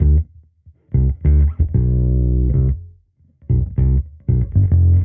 Index of /musicradar/sampled-funk-soul-samples/95bpm/Bass
SSF_PBassProc1_95D.wav